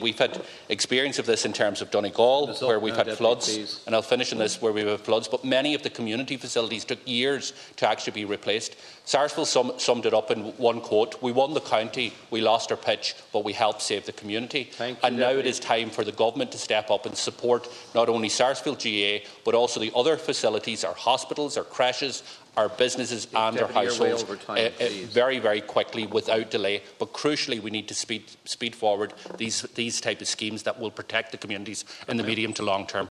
Commenting on the matter in the Dáil Deputy Pearse Doherty said Donegal community facilities have taken years to be replaced following flooding.